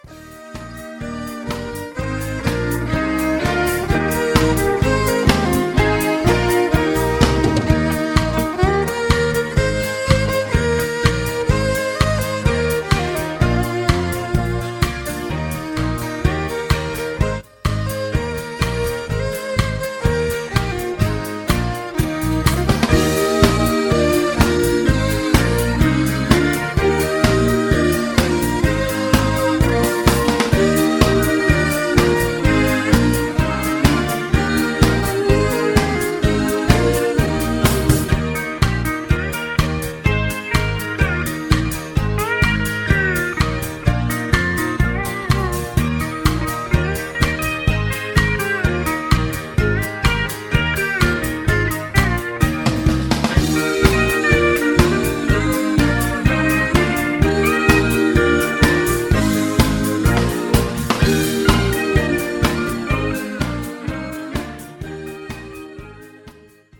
Low Key
Singing Calls